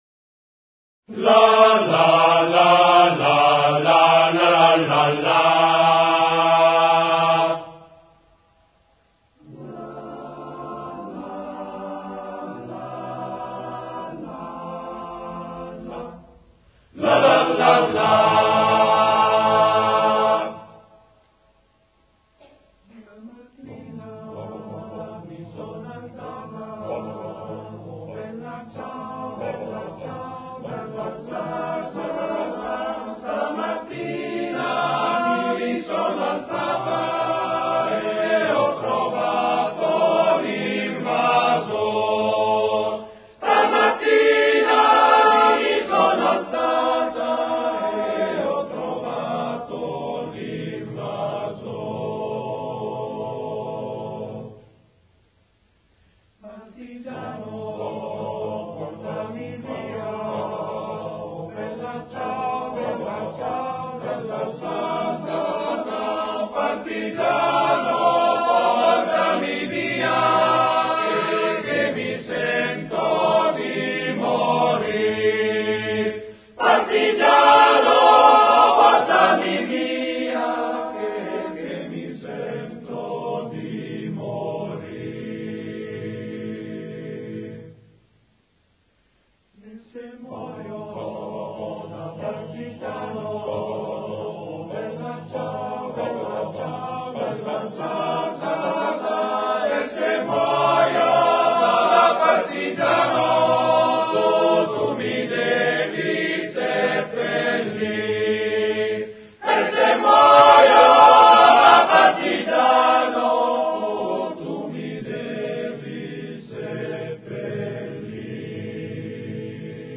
[ voci virili ]